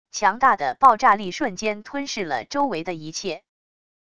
强大的爆炸力瞬间吞噬了周围的一切wav音频